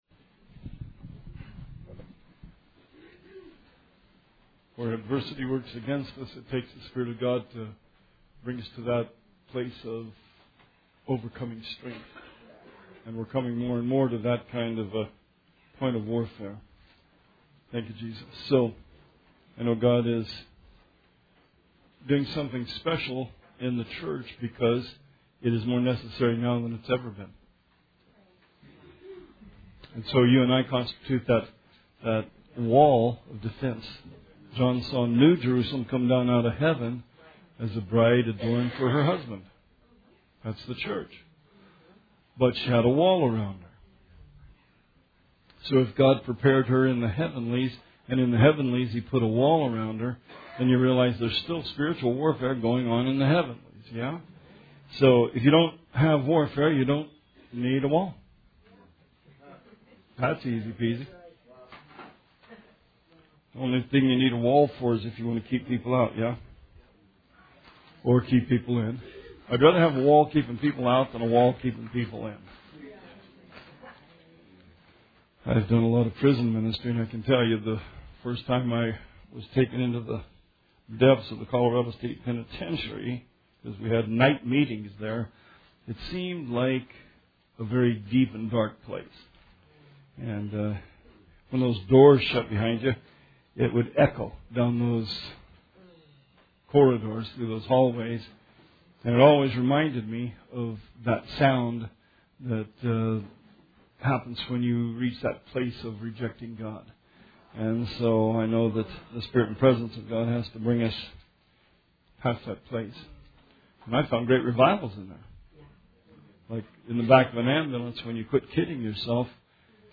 Sermon 3/18/18